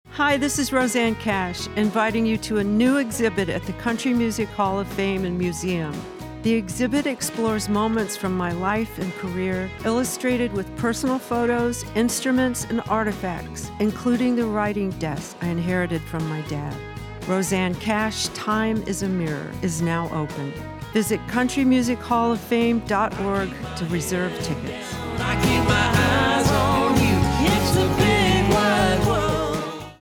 Produced Spot
Rosanne Cash :30 Radio Spot